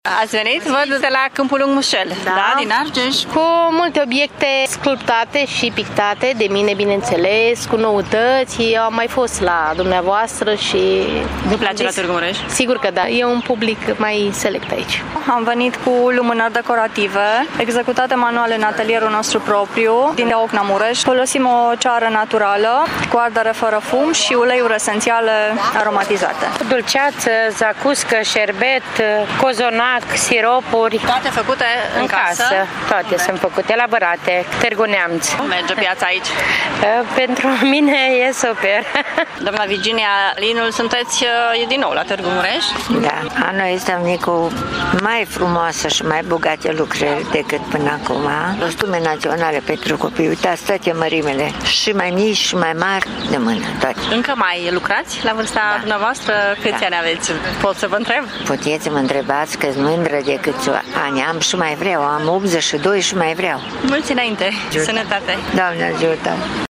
O bună parte dintre expozanți au venit încrezători din alte județe ale țării, știind că la Târgu-Mureș fac afaceri bune: